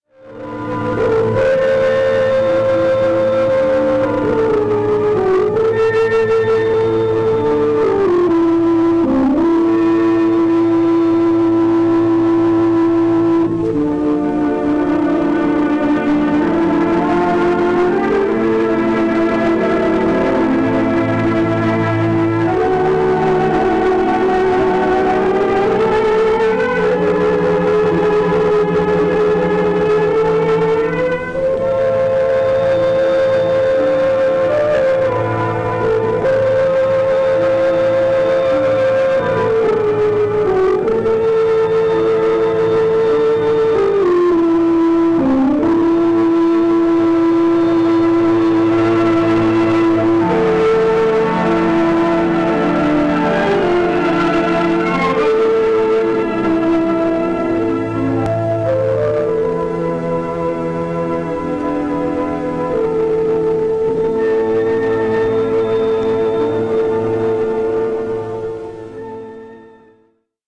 Original Track Music (1.00)